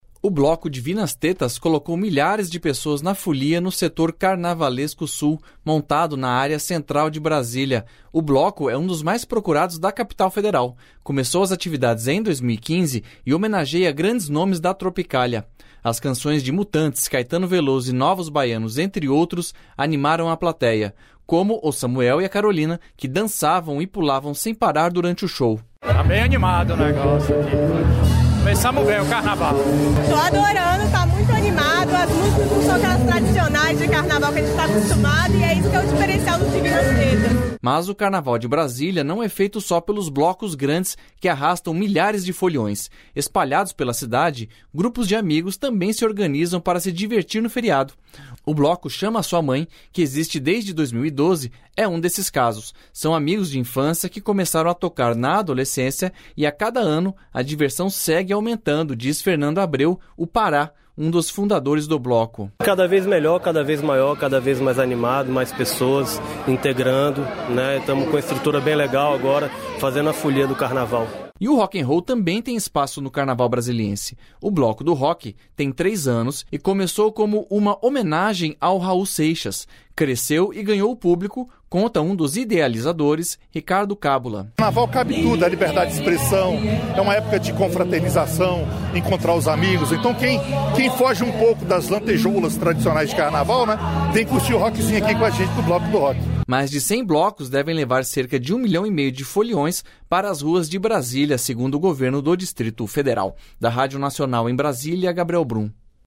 O bloco “Divinas Tetas” colocou milhares de pessoas na folia no Setor Carnavalesco Sul, montado na área central de Brasília.